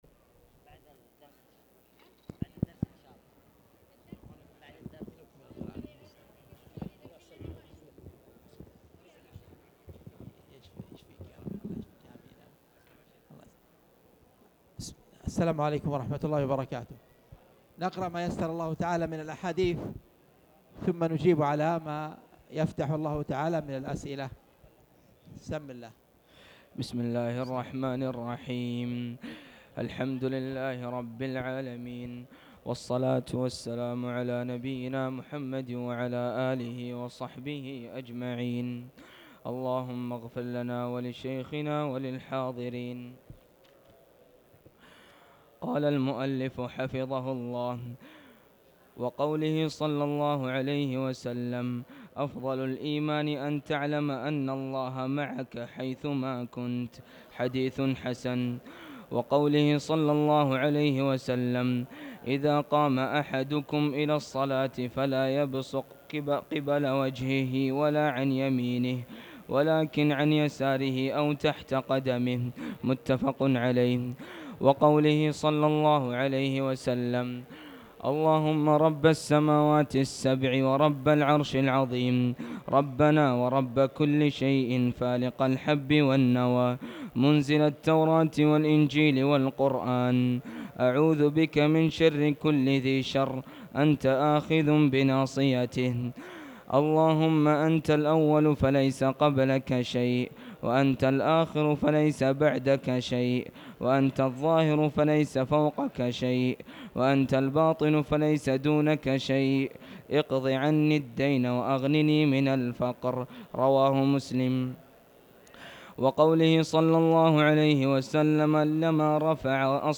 تاريخ النشر ٢٧ ربيع الثاني ١٤٣٩ هـ المكان: المسجد الحرام الشيخ